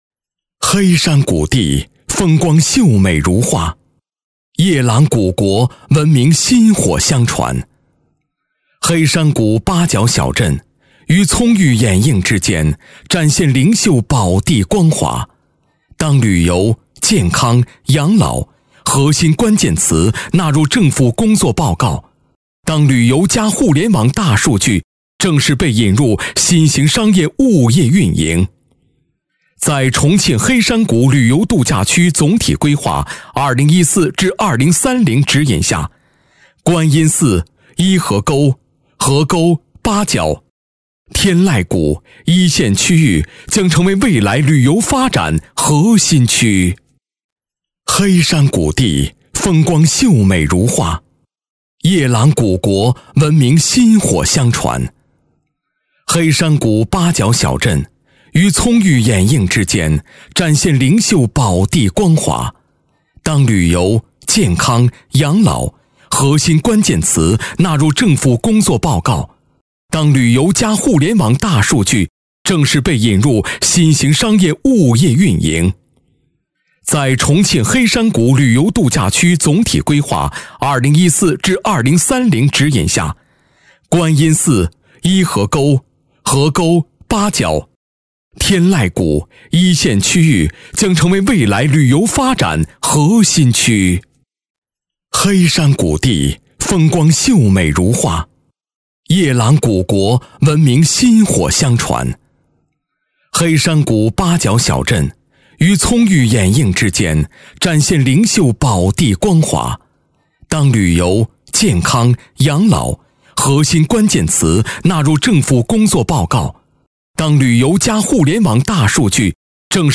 方言中年素人 、男绘本故事 、动漫动画游戏影视 、看稿报价男B123 方言闽南语 男福 素人 - 样音试听_配音价格_找配音 - voice666配音网